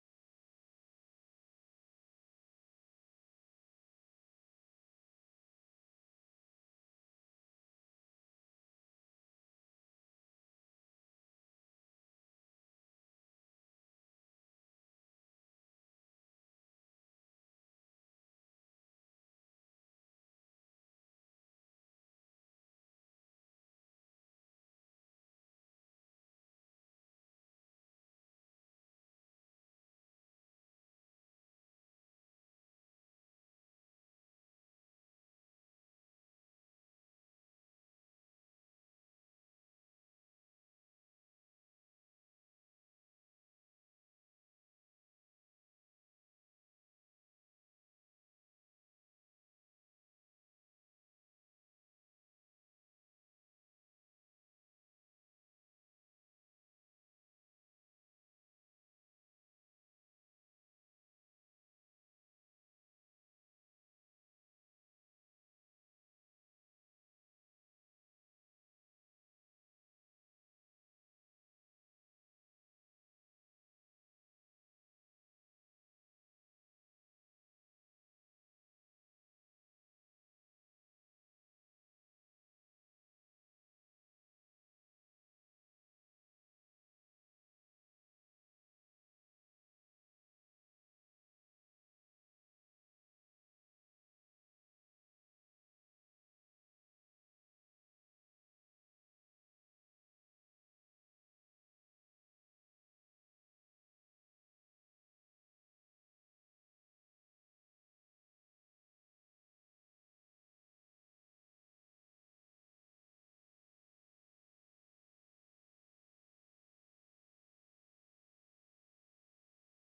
During the service we will celebrate communion.
February 6, 2022 (Morning Worship)